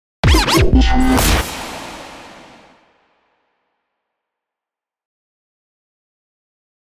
FX-022-IMPACT-COMBO.mp3